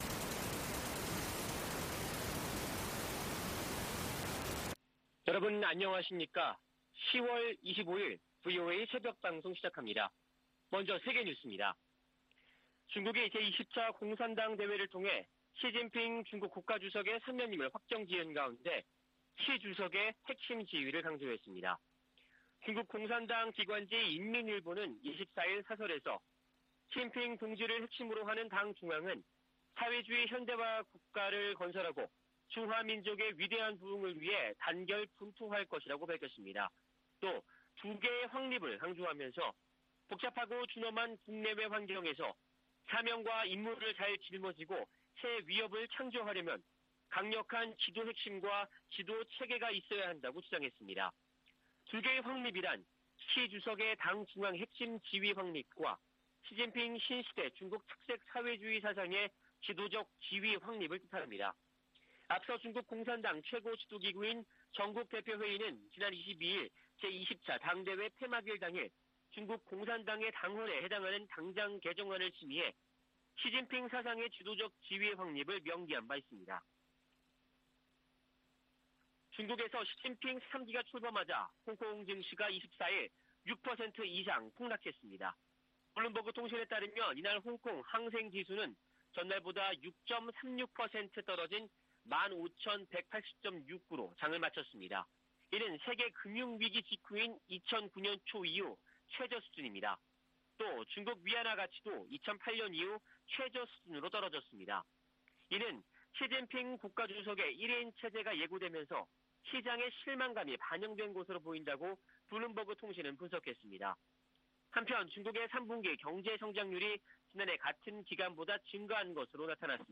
VOA 한국어 '출발 뉴스 쇼', 2022년 10월 25일 방송입니다. 한국 합동참모본부는 24일 서해 백령도 서북방에서 북한 상선이 북방한계선(NLL)을 침범해 경고 통신과 경고사격으로 퇴거 조치했다고 밝혔습니다. 북대서양조약기구는 한국과 함께 사이버 방어와 비확산 등 공통의 안보 도전에 대응하기 위해 관계를 강화하는데 전념하고 있다고 밝혔습니다. 국제자금세탁방지기구가 북한을 11년째 대응조치를 요하는 '고위험 국가'에 포함했습니다.